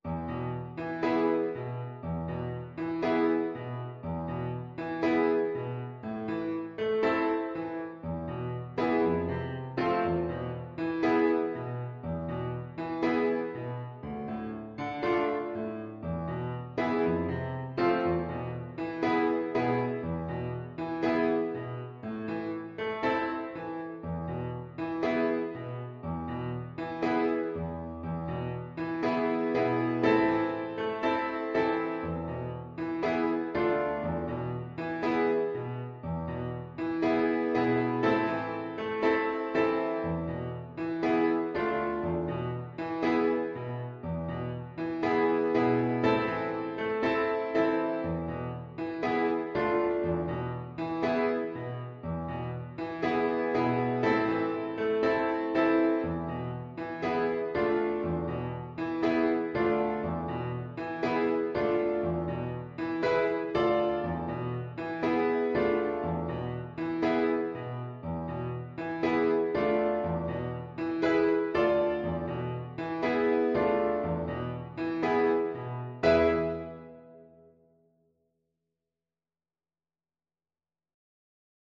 Play (or use space bar on your keyboard) Pause Music Playalong - Piano Accompaniment Playalong Band Accompaniment not yet available transpose reset tempo print settings full screen
A minor (Sounding Pitch) (View more A minor Music for Cello )
4/4 (View more 4/4 Music)
Allegro moderato =120 (View more music marked Allegro)